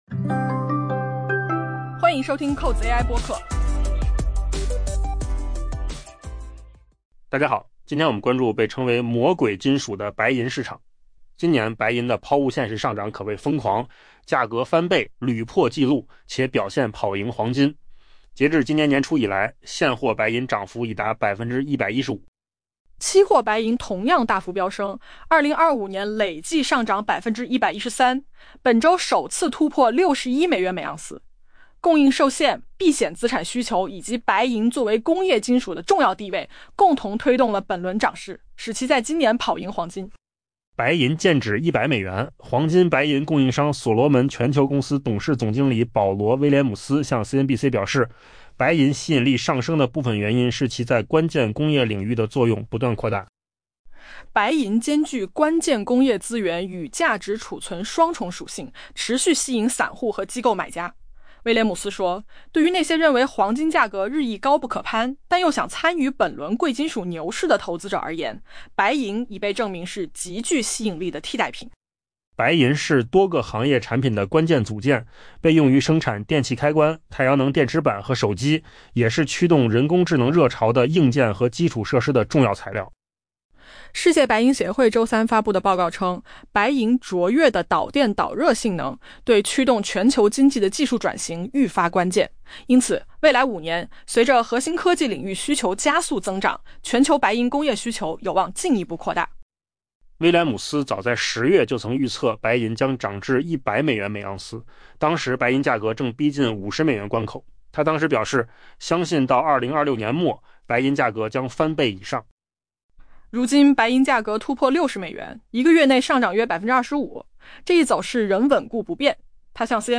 AI 播客：换个方式听新闻 下载 mp3 音频由扣子空间生成 今年白银的抛物线式上涨可谓疯狂，价格翻倍、屡破纪录，且表现跑赢黄金。